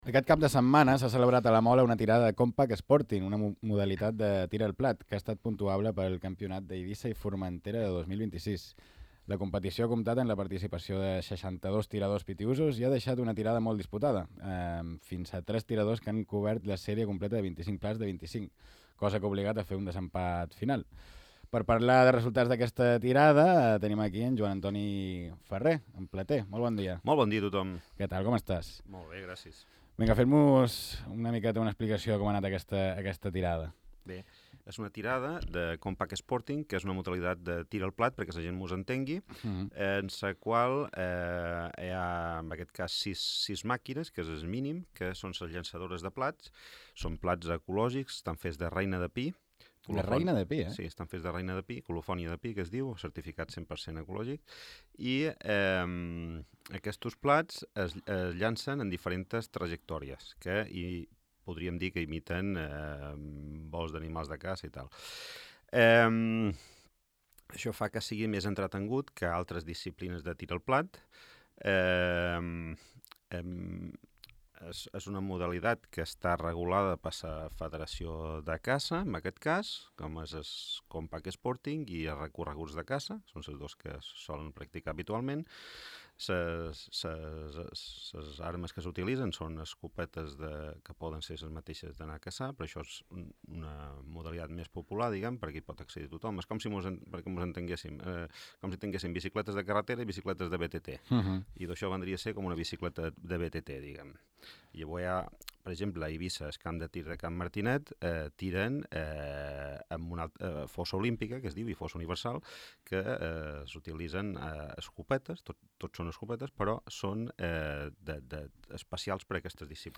En una entrevista a Ràdio Illa